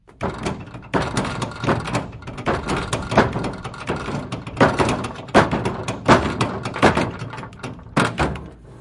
冲击、撞击、摩擦 工具 " 金属罐敲击声
Tag: 工具 工具 崩溃 砰的一声 塑料 摩擦 金属 冲击